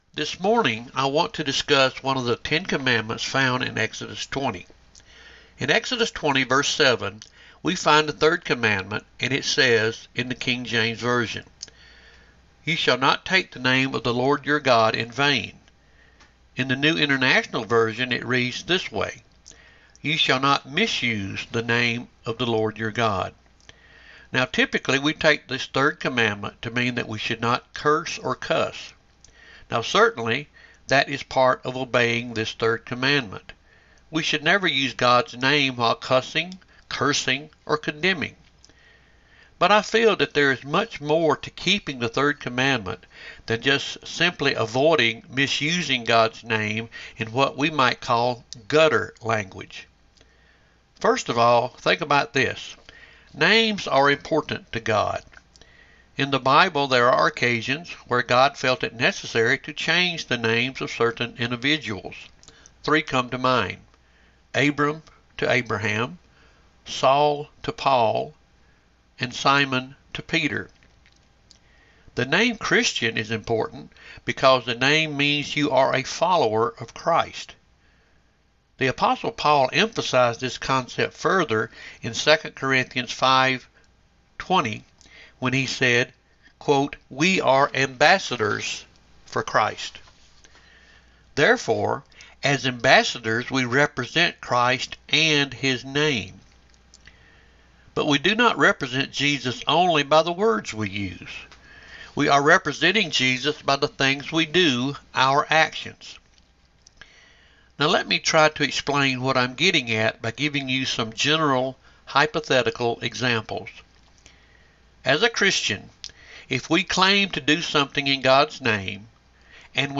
Tuesday 8/16 Devotion – Lyerly United Methodist Church